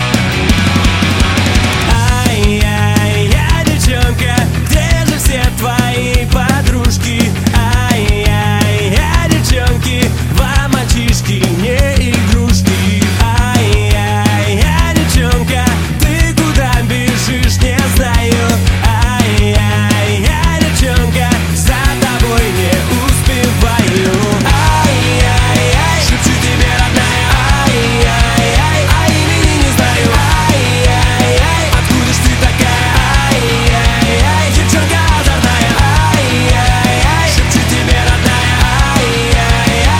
Рок версия